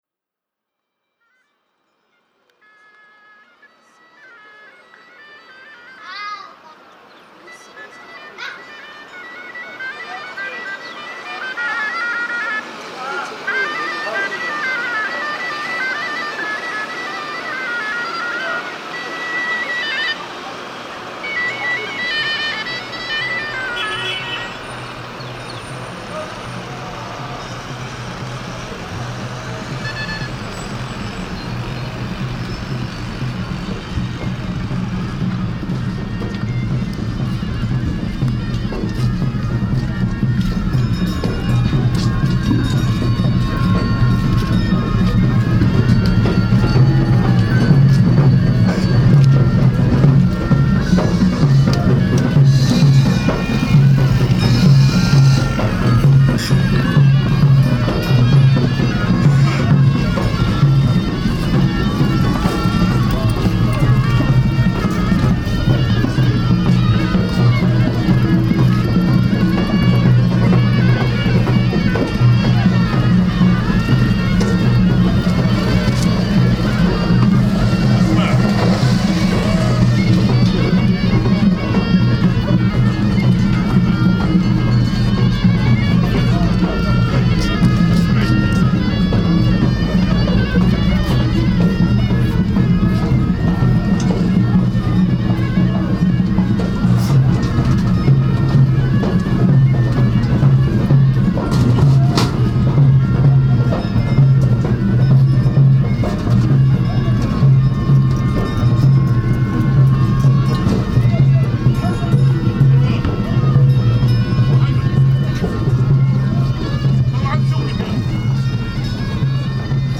This is a series of recordings made in 1999 while exploring the streets of Marrakesh. A pair of binaural microphones were used to capture the rapid change in sound as I walked through the market from stall to stall, through traffic, and through the fabled tourist trap - Djeema El Fna. The title of the piece was inspired by the rapid audio changes that resulted from just taking a few steps in any direction. A portion of the recordings also took place in a chameleon market.